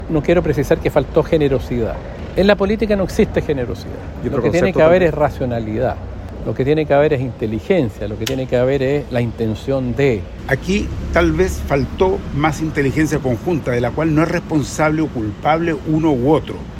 El diputado Jaime Mulet (FRVS) evitó apuntar contra una colectividad en específico, pero remarcó que hizo falta la “racionalidad”, ya que el acuerdo sí era posible a su parecer.
En la misma línea, el timonel de Acción Humanista, Tomás Hirsch, afirmó que si bien su partido abogó siempre por construir una lista única, a su juicio, faltó “inteligencia conjunta”.